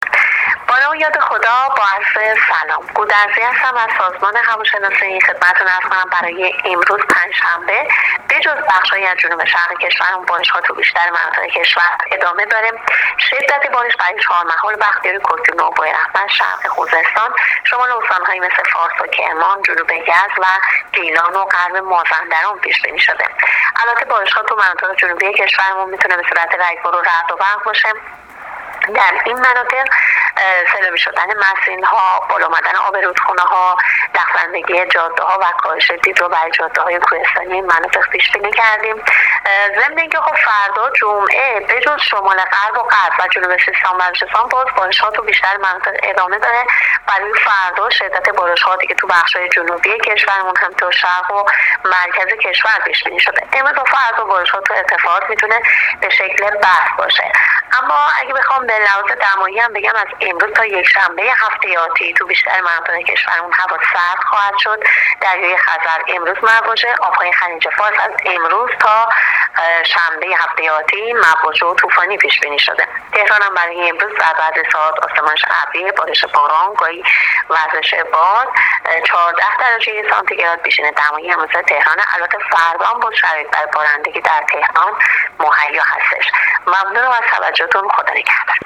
کارشناس سازمان هواشناسی کشور در گفت‌وگو با رادیو اینترنتی وزارت راه و شهرسازی، آخرین وضعیت آب و هوای کشور را تشریح کرد.
گزارش رادیو اینترنتی از آخرین وضعیت آب‌‌و‌‌‌هوای ۲۲ آبان